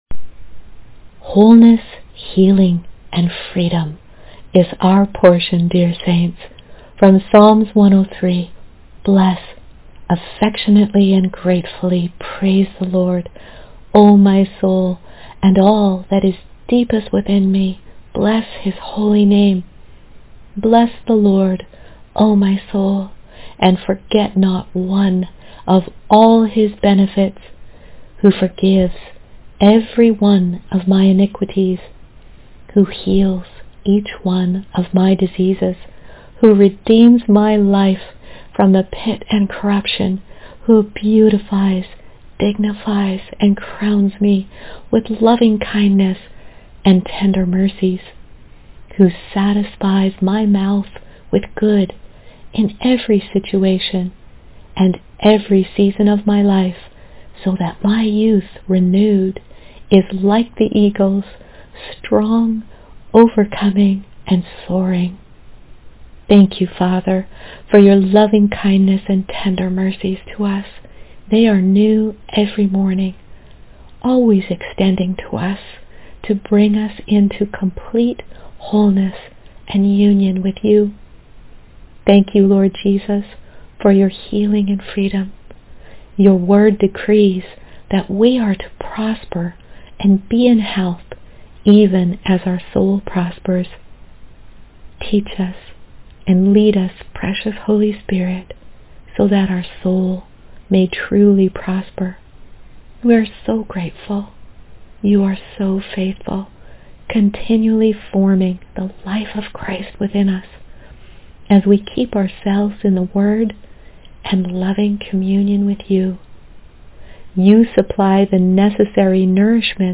Wholeness: prayer and verse